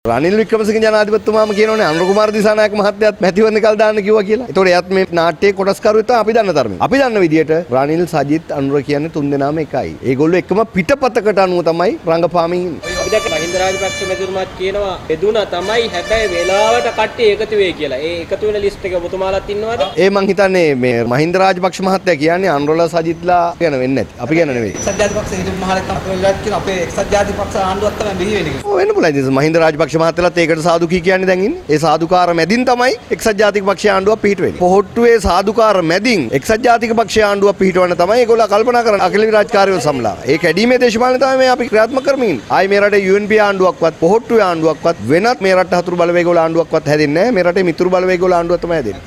කොළඹදී මාධ්‍ය වෙත අදහස් පල කරමින් ඔහු මේ බව ප්‍රකාශ කළා .